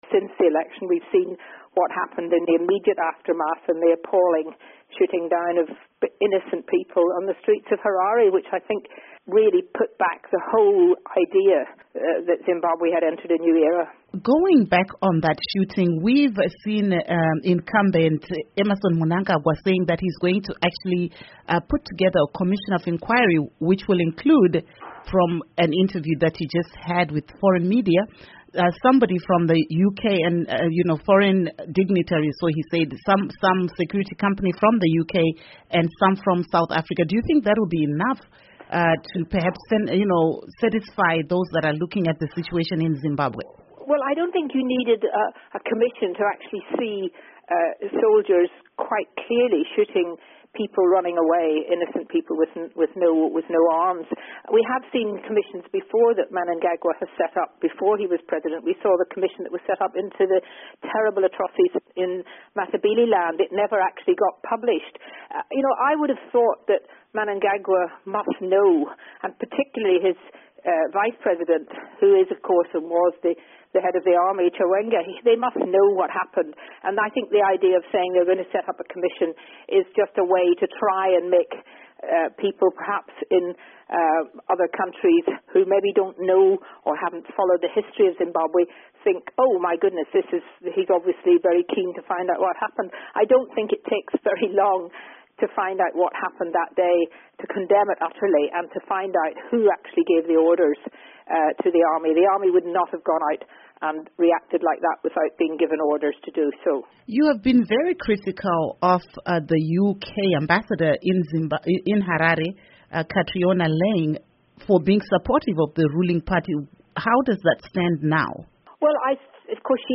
Interview With Kate Hoey